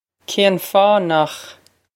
Pronunciation for how to say
Kayn faw nokh
This is an approximate phonetic pronunciation of the phrase.